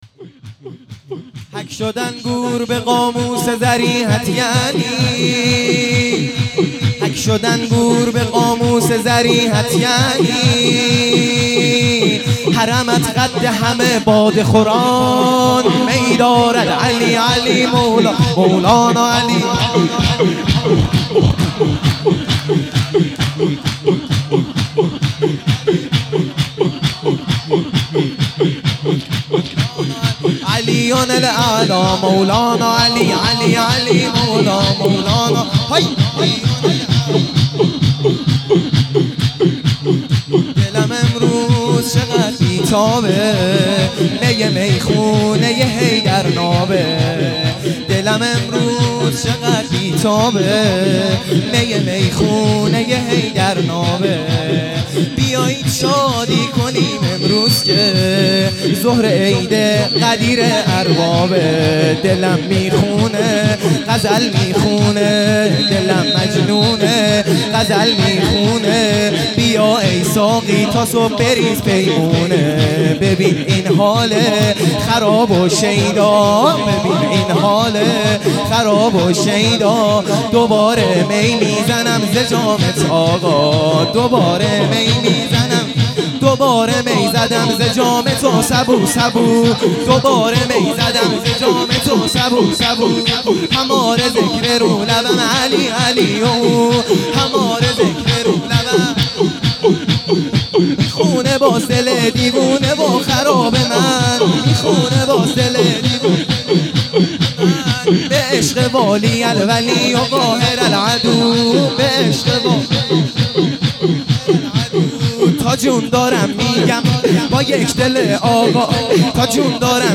سرود | دلم امروز چقدر بی تابه